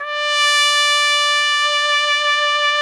Index of /90_sSampleCDs/Roland L-CDX-03 Disk 2/BRS_Piccolo Tpt/BRS_Picc.Tp 2 St